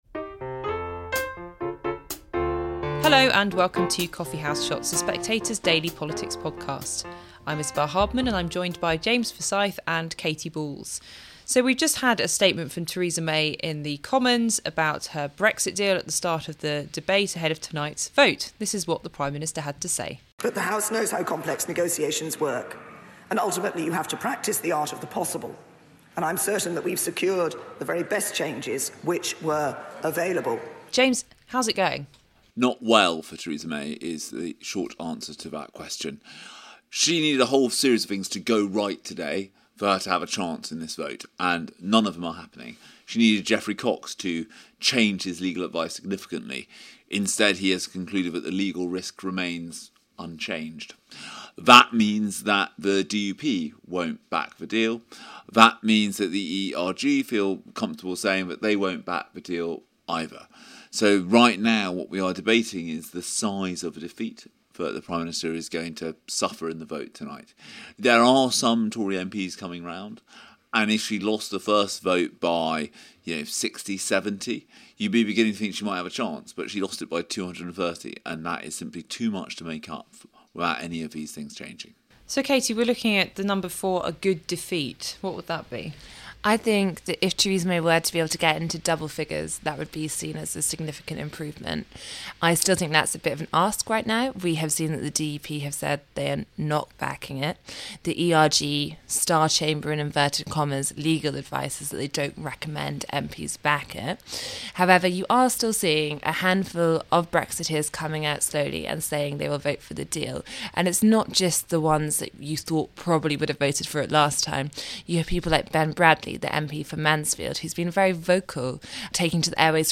Presented by Isabel Hardman.